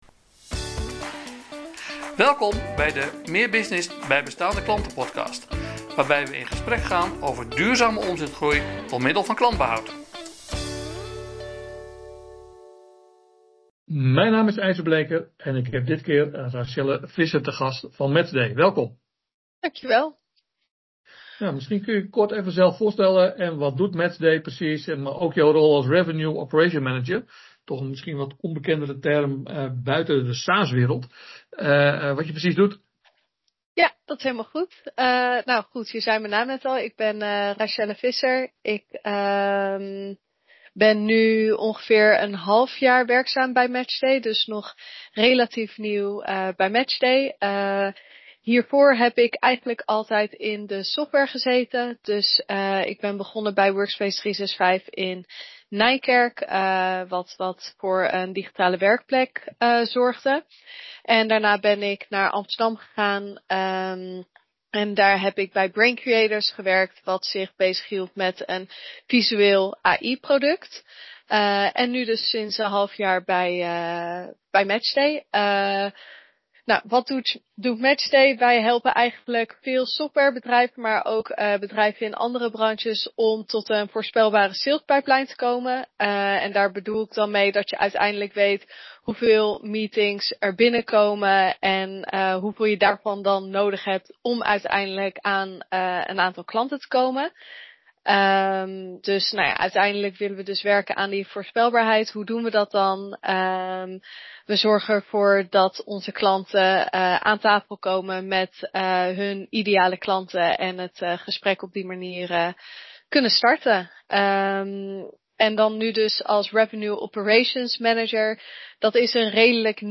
In deze aflevering een gesprek